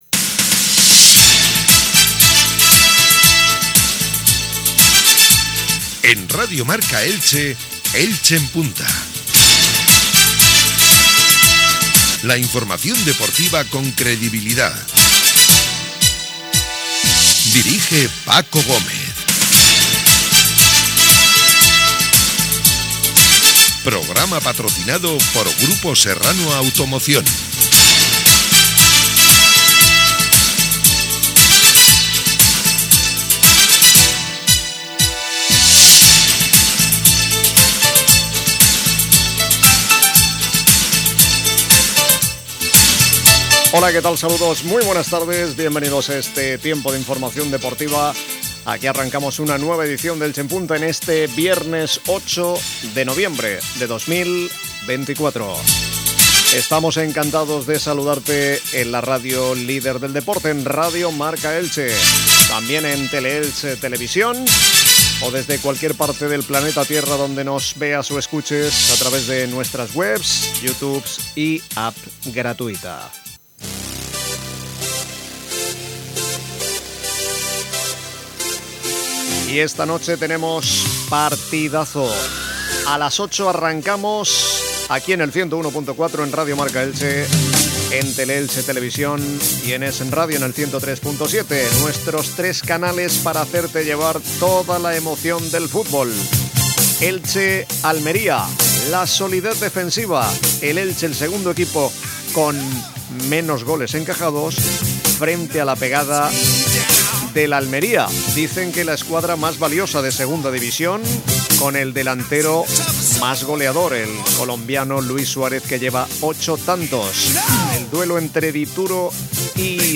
08/11/24 Previa Elche-Almería; ruedas de prensa de Sarabia y Rubi.